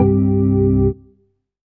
ORGAN-02.wav